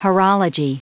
Prononciation du mot horology en anglais (fichier audio)
Prononciation du mot : horology